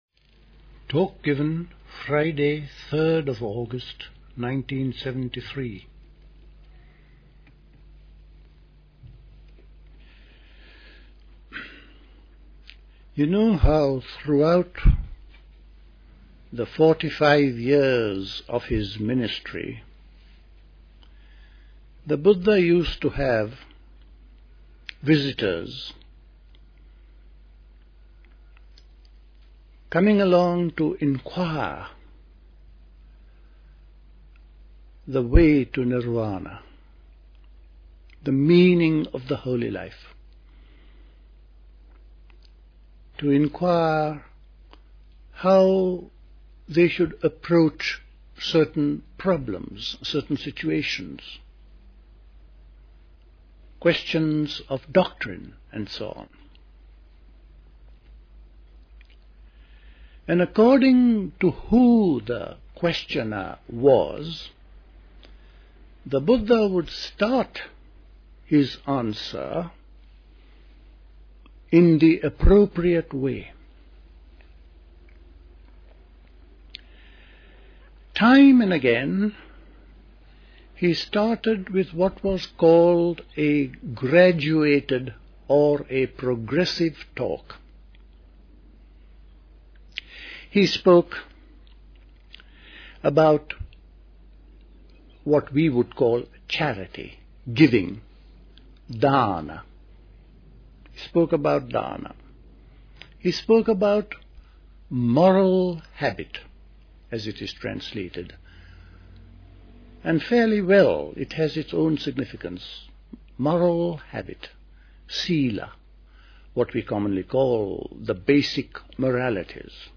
If we are sensitive enough, we awake to the fact that “my” body in reality is the universal body, including psyche, mind, spirit — One Reality, a Universe. Recorded at the 1973 Dilkusha Summer School.